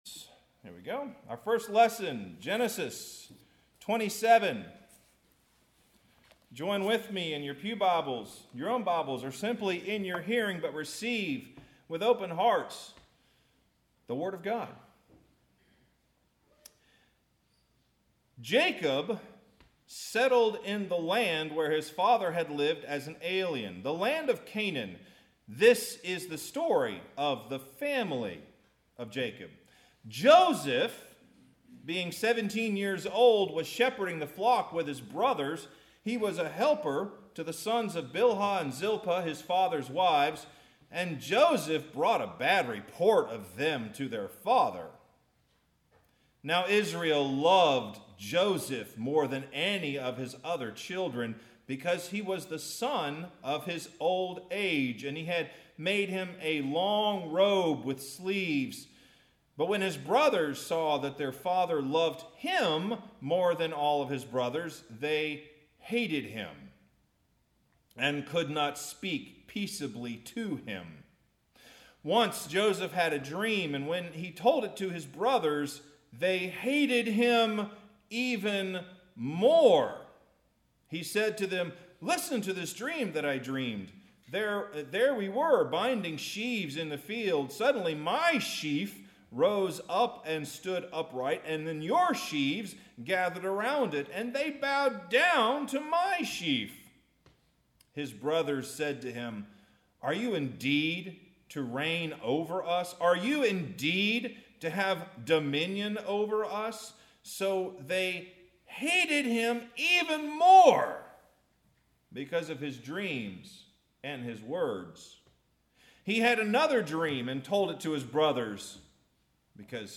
Genesis 37:1-28; Luke 4:1-13 March 27, 2022 Pride, envy, and arrogance in our dreams for God Congratulations! It is always nice to begin a sermon with a “congratulations.”